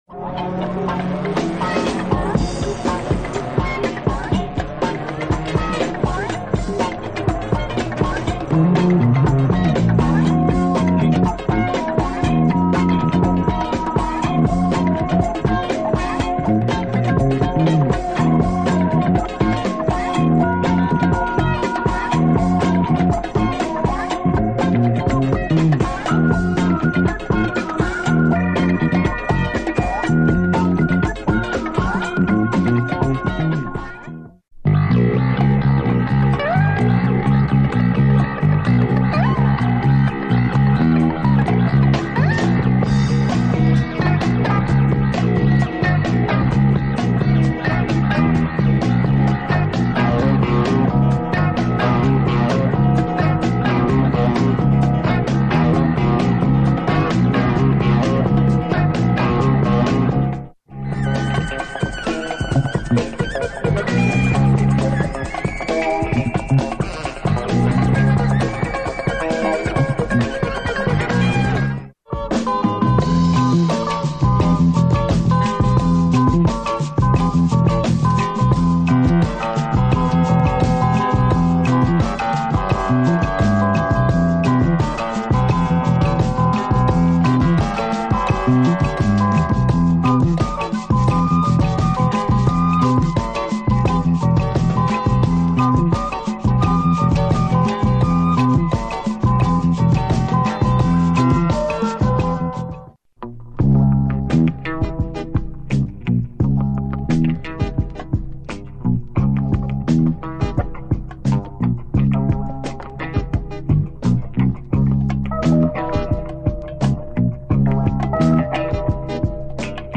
European funk album
hard funk